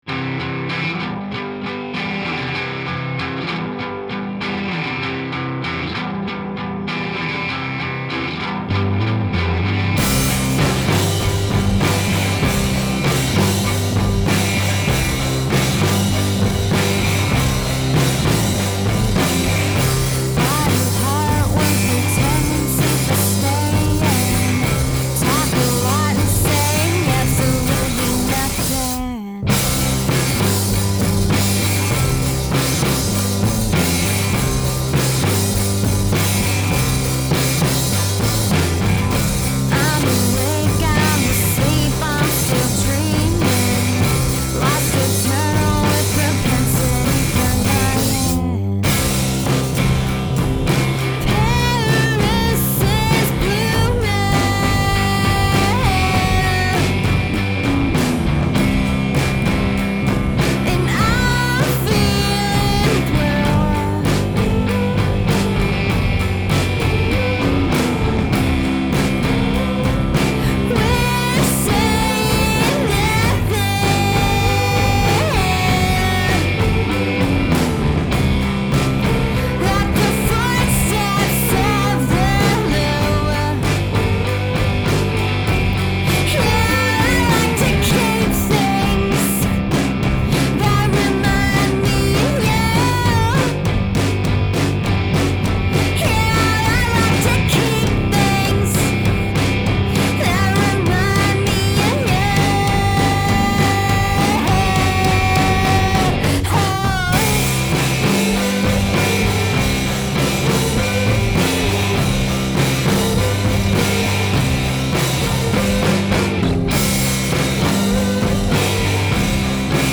vocals and guitar
Drums
Bass
in a small room in Brooklyn, NY.